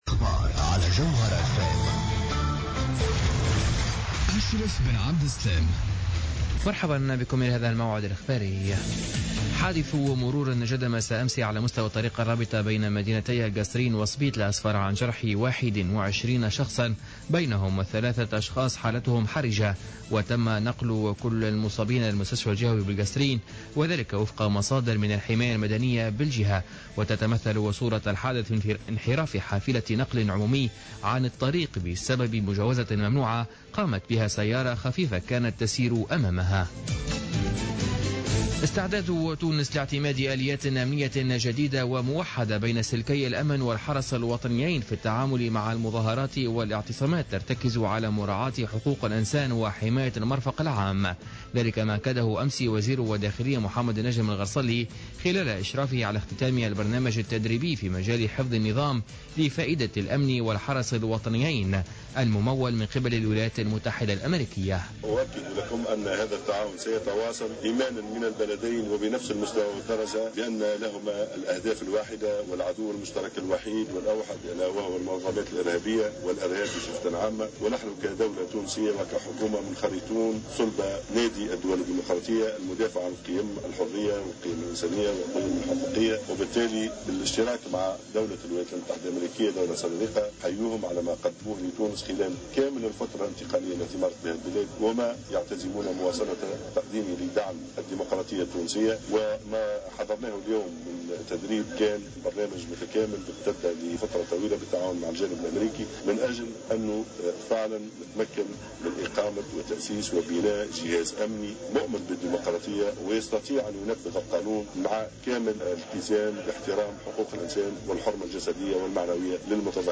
نشرة أخبار منتصف الليل ليوم السبت 29 أوت 2015